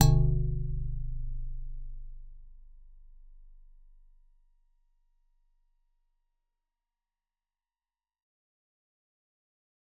G_Musicbox-G0-f.wav